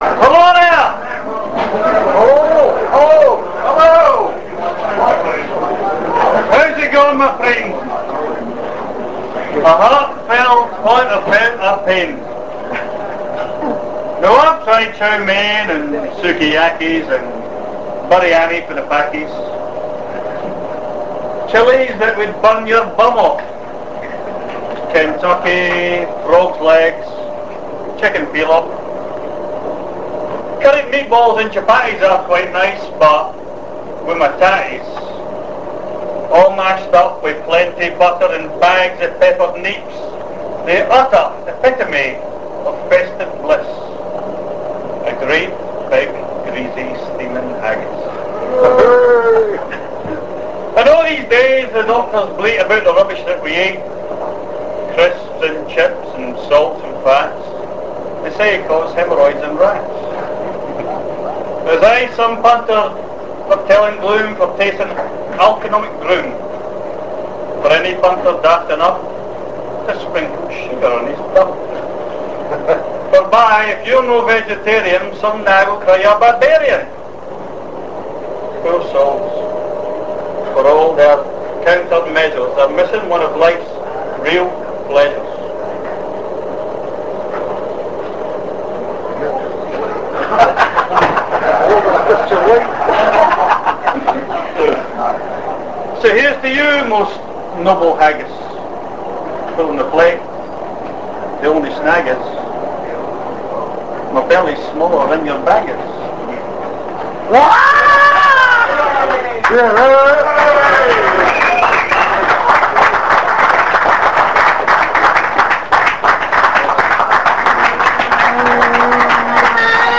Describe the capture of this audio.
London Hibs Annual Burns Night Supper was held on Saturday 30th January 1999 at the Holyrood Pub, Wells Street, off Oxford Street, and a good time was had by one and all.